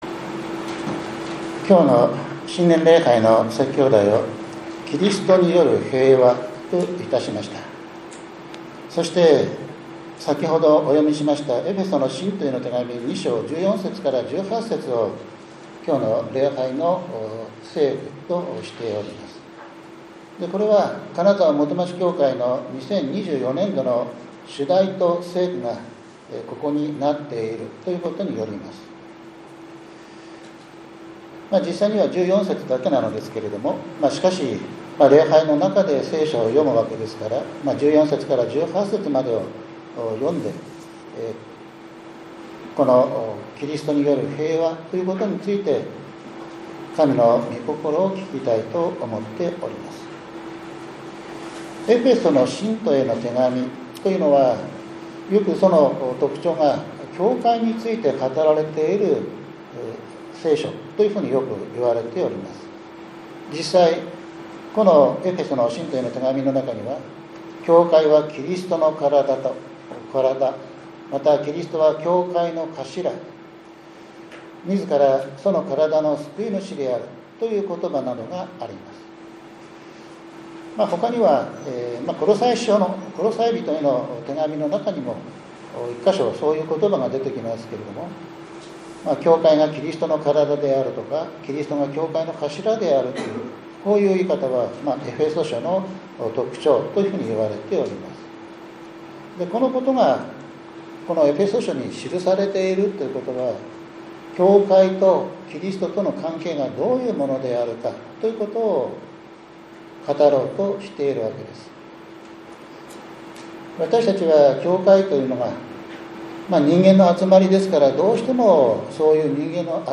キリストによる平和 2025年 新年礼拝